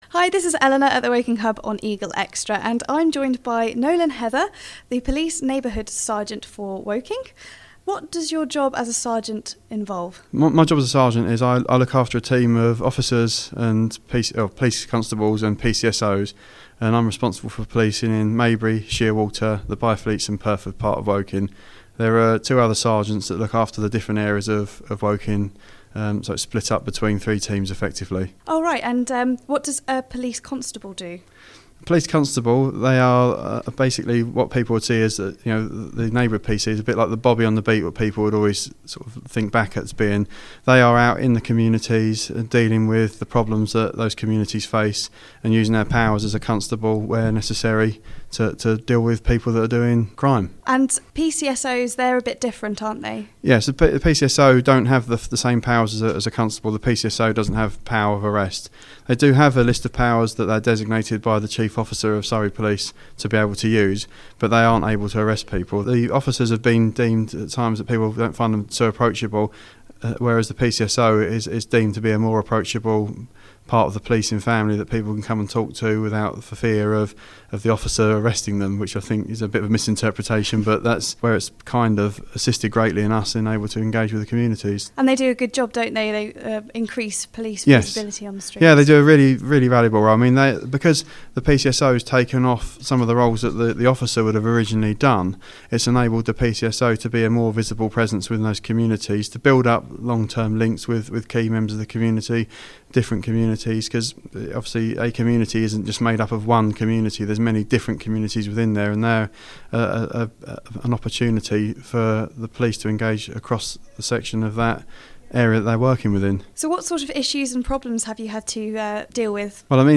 Eagle Extra interview